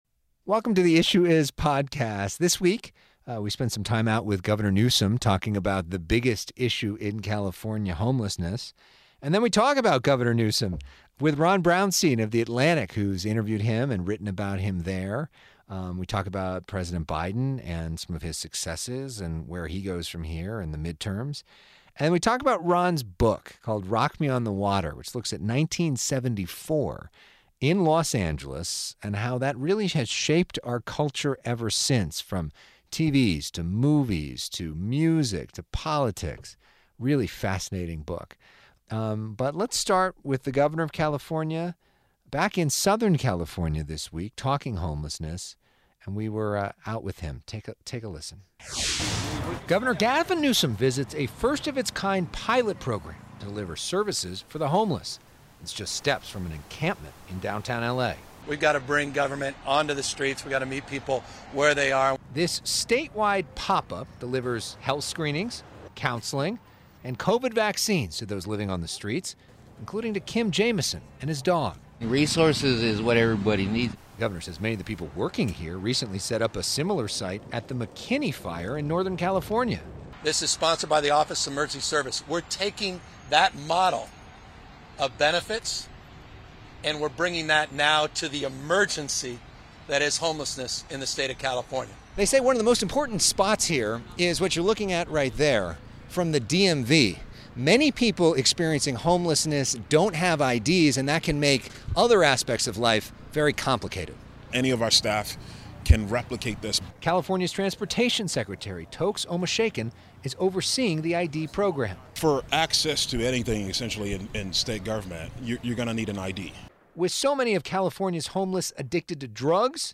This week on "The Issue Is," a look ahead into the political future and a look back into the cultural past during an in-depth conversation with journalist and author Ronald Brownstein.